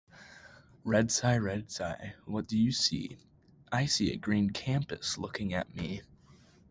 A repetitive, rhythmic chant follows Red Cy as it spots different colored campus sights, ending with the Cyclones seeing them all looking back.